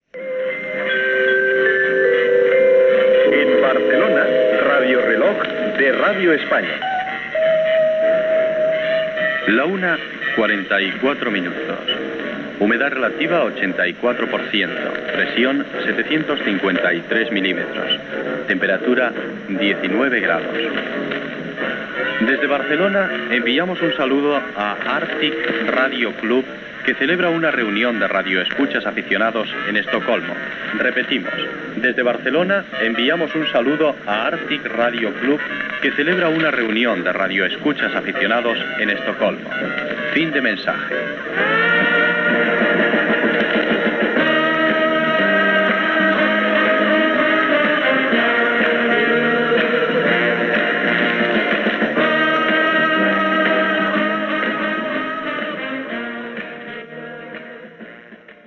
Identificació, hora, estat del temps, salutació a l'Artic Radio Club de Suècia i tema musical.